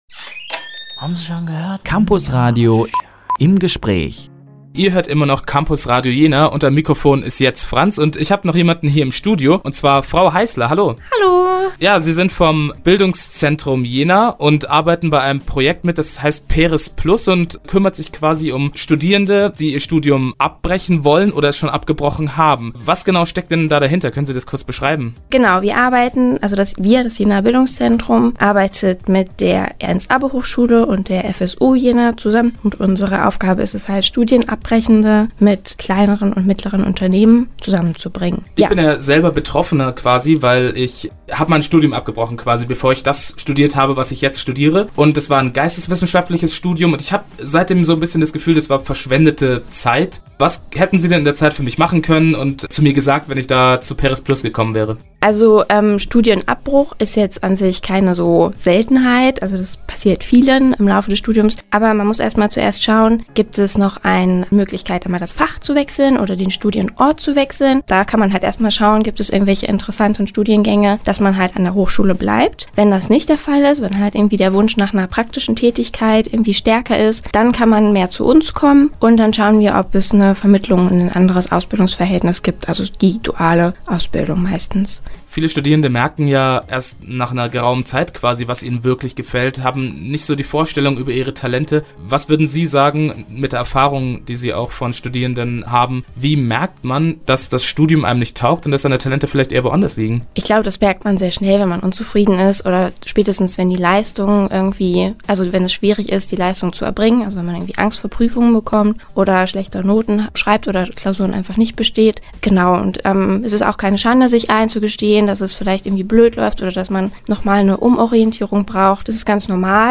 Im Gespräch: